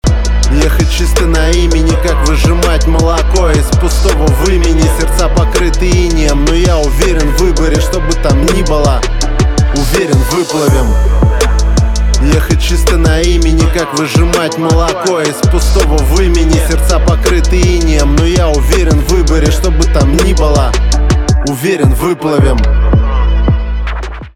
русский рэп , битовые , басы